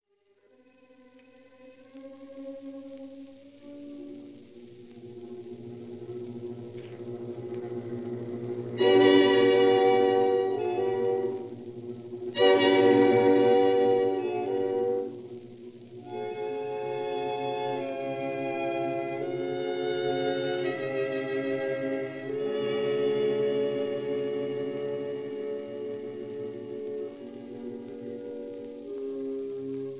clarinet
bassoon
horn
violins
viola
cello
double-bass
Recorded in Société suisse de radiodiffusion
studio, Geneva in July 1948